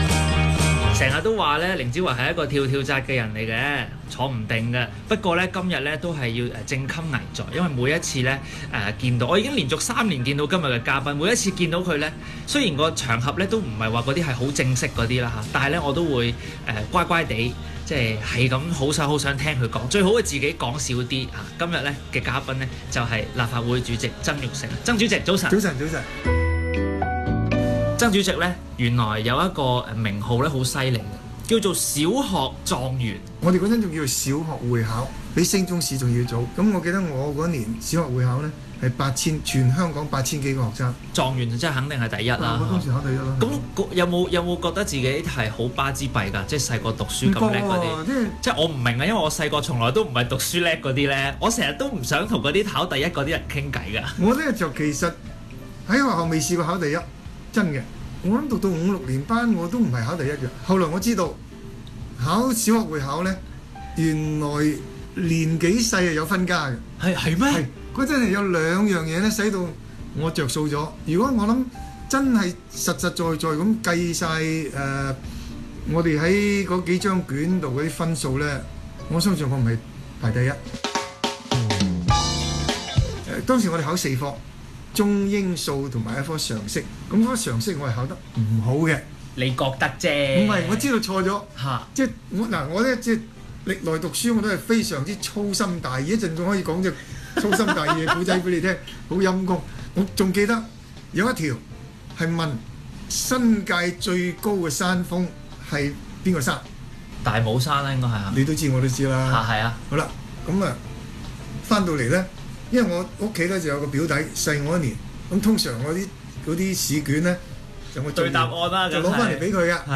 香港電台《晨光第一線》訪問